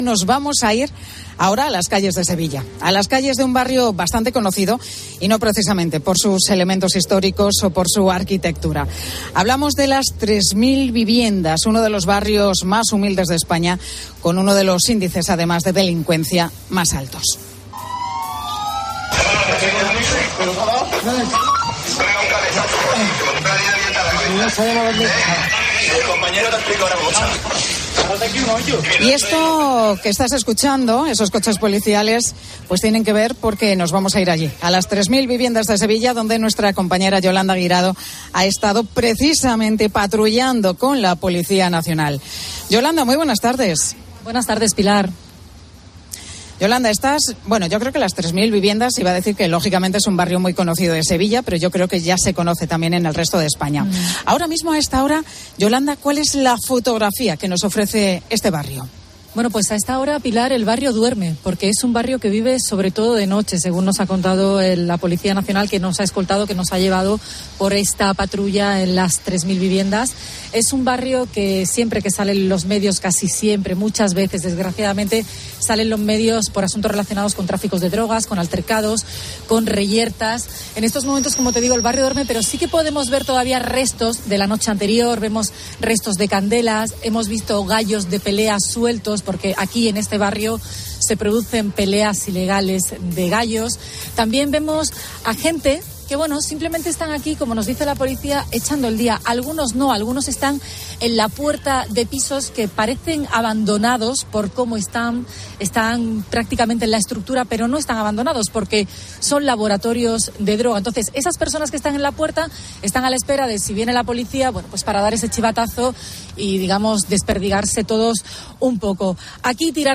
Un equipo de COPE Andalucía recorre las calles de las Tres Mil Viviendas con un patrullero de la Policía Nacional del Distrito Sur de Sevilla
Décadas más tarde, un equipo de COPE Andalucía sube a un patrullero de la Policía Nacional para saber cuánto hay de leyenda y cuánto de realidad sobre el barrio.